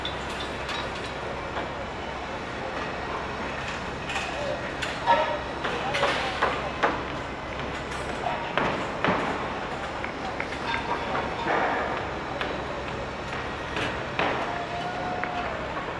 construction.wav